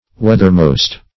Weathermost \Weath"er*most`\, a.